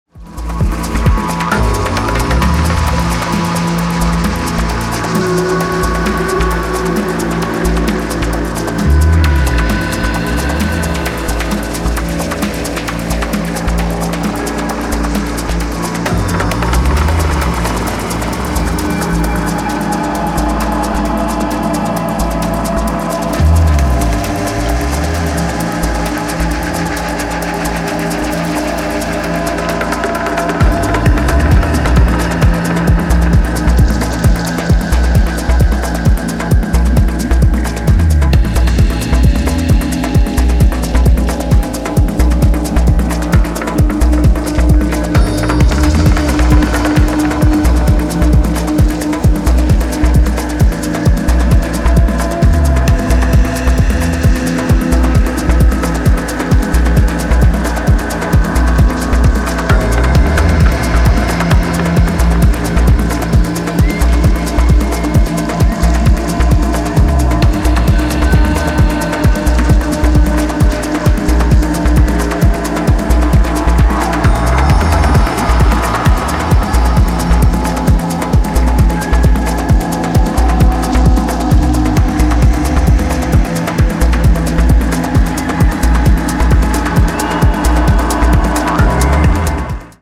緻密にオートメーションを描かれたダビーにさざめくフレーズが陶酔感に溢れるディープ・テクノマナーの133BPM